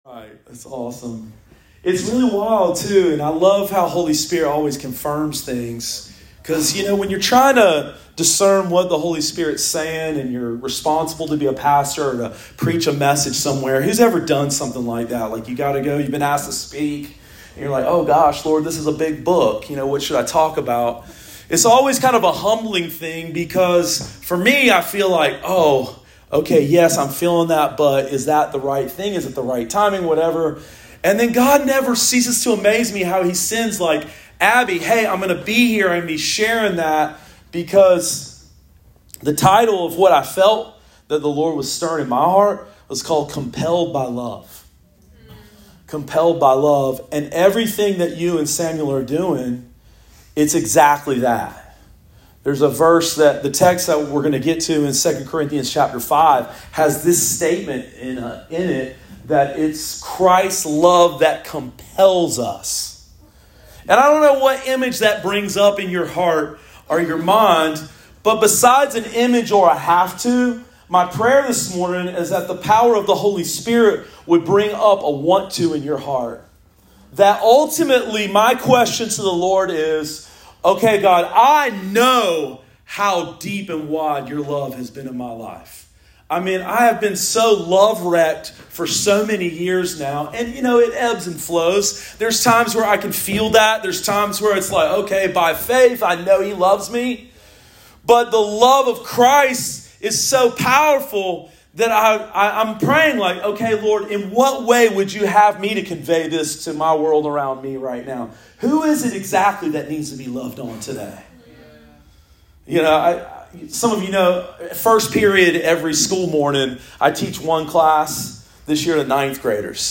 Sermon of the Week: 10/22/23 – RiverLife Fellowship Church